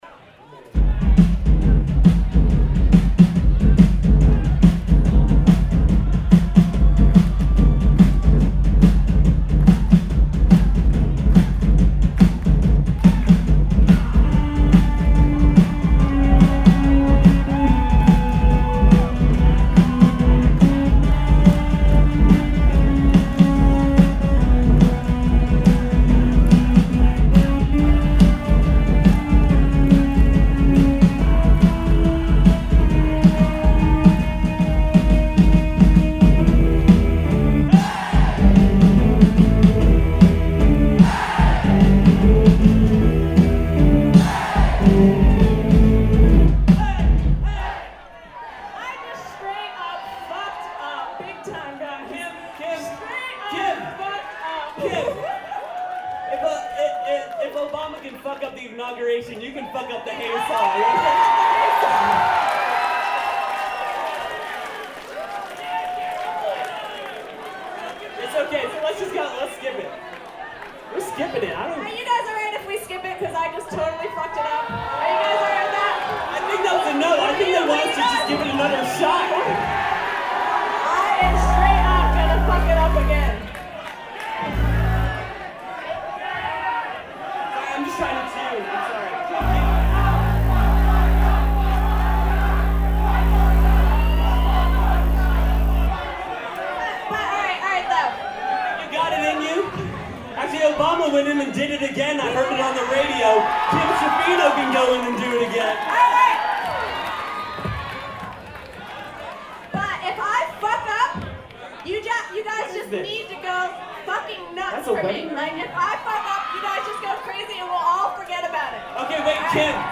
a keyboard and drums duo